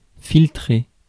Ääntäminen
IPA: [fil.tʁe]